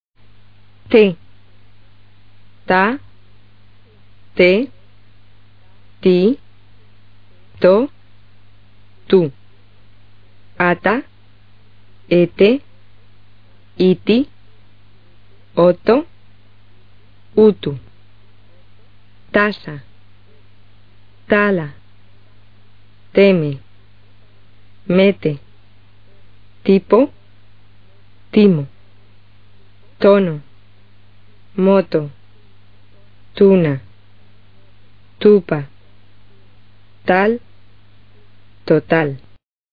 T发音：